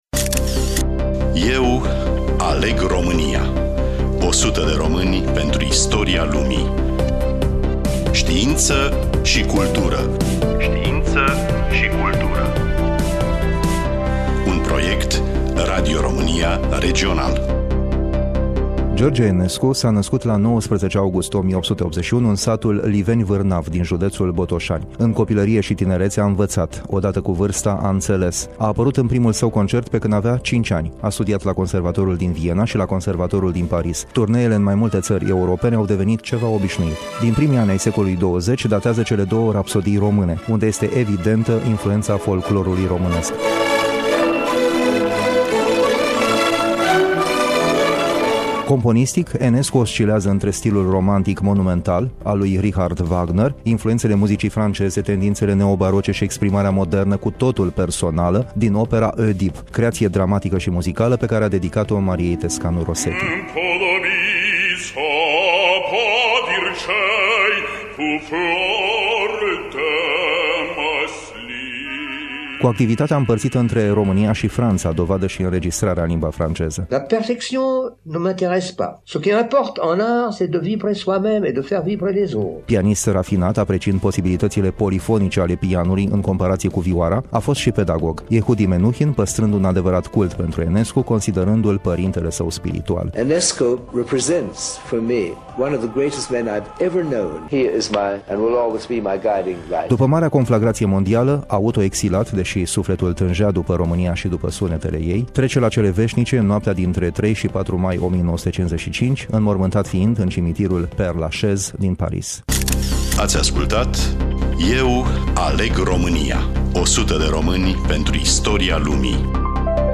Studioul: RADIO ROMÂNIA IAȘI